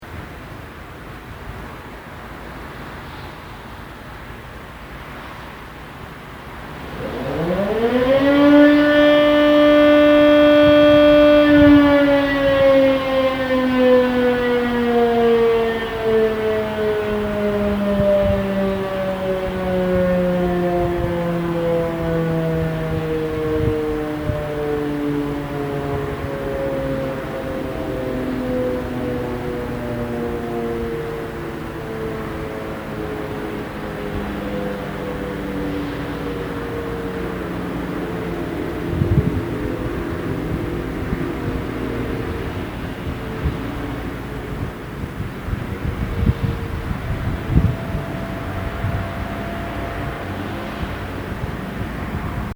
Nu, een dag later, toch maar weer onrust met een eigen sirene geluid: je kan ervoor kiezen.